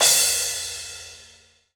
Drums/CYM_NOW! Cymbals